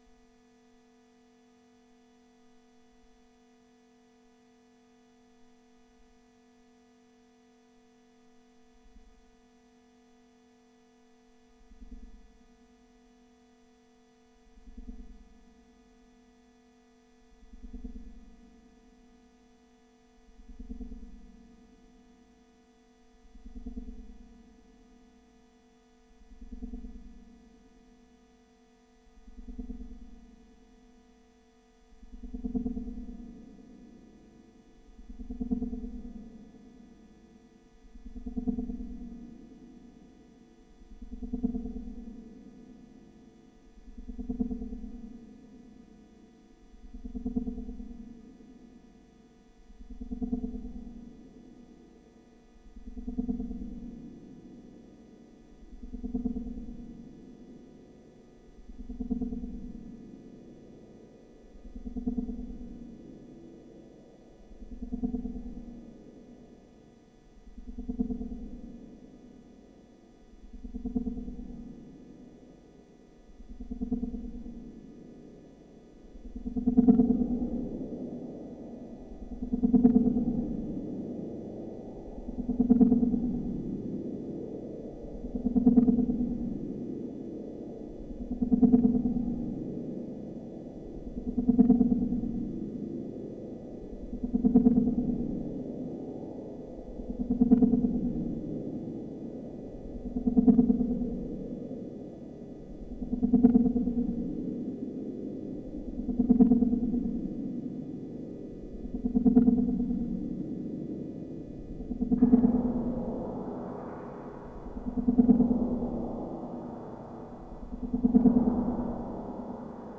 Son vibrant, une seule voix. Utilisation de la réverbération à ressort A199 Doepfer.